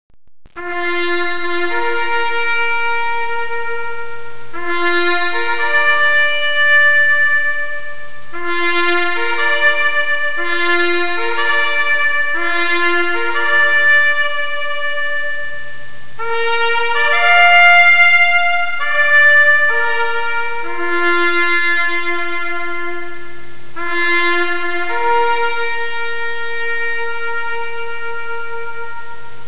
taps.wav